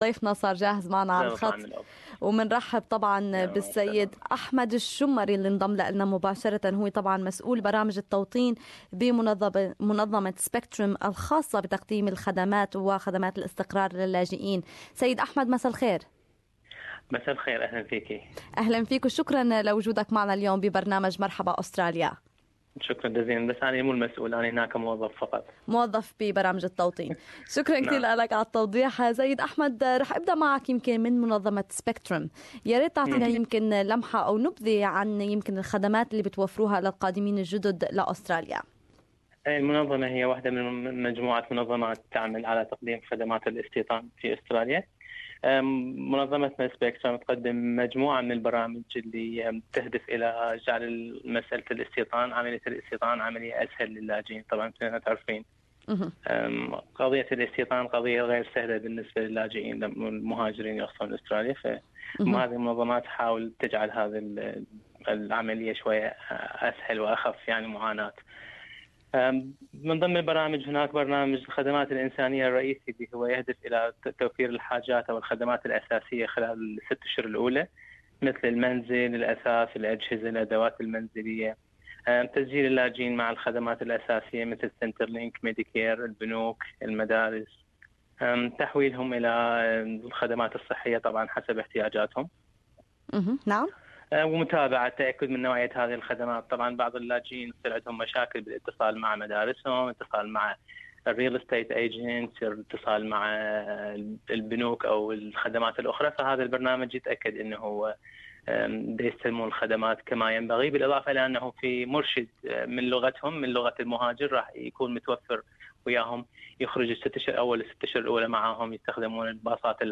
لقاءً مباشرا في برنامج مرحباً أستراليا